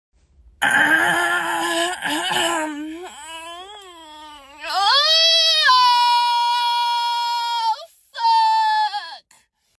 Funny Sounds meme green screen sound effects free download